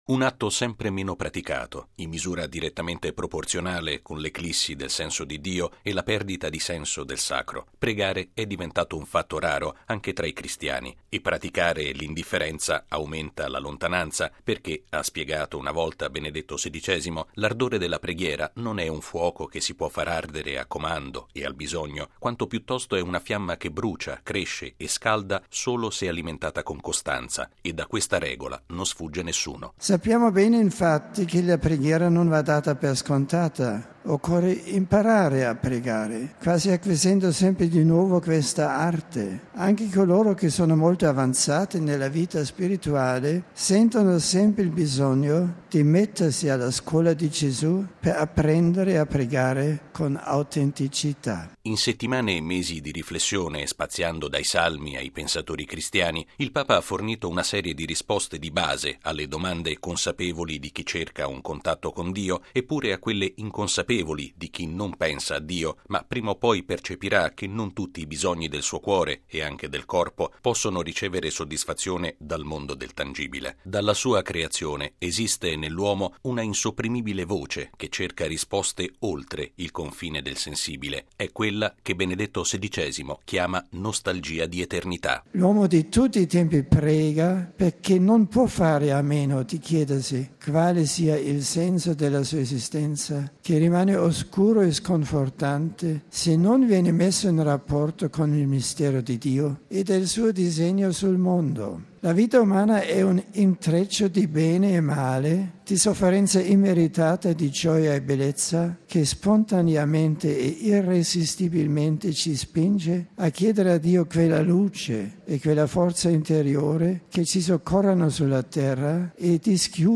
(Udienza generale 4 maggio 2011)
(Udienza generale 11 maggio 2011)